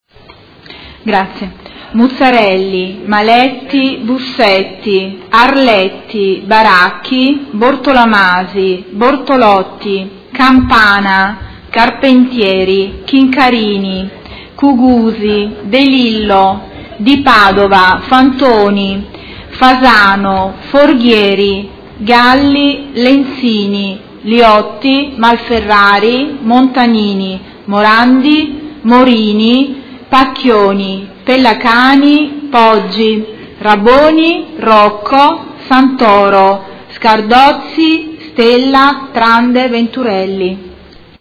Appello
Segretario Generale